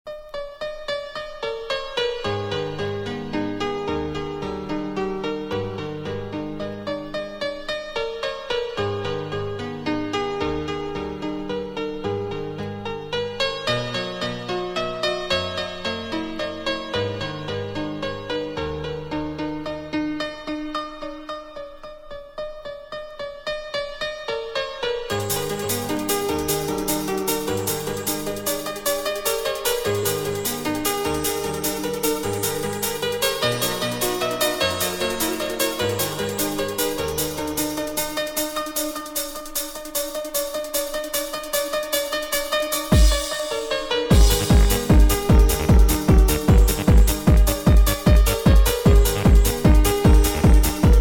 beethovenfurelistechno.mp3